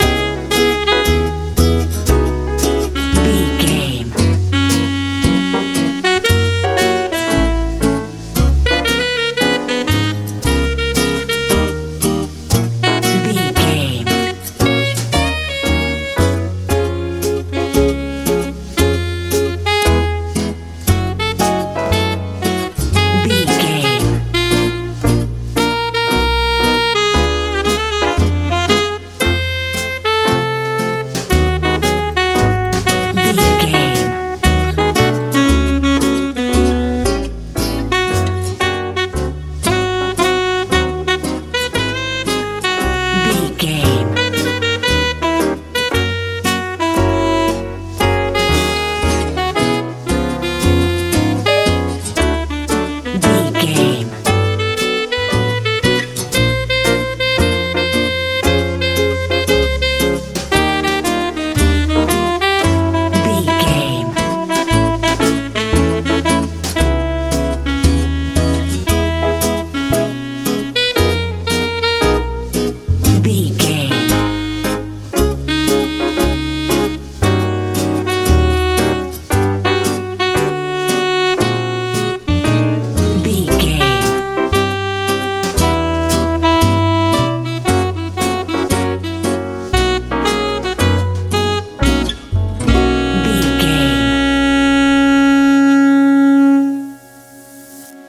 jazz
Aeolian/Minor
C♯
light
magical
mellow
piano
saxophone
acoustic guitar
bass guitar
drums
tranquil
calm
elegant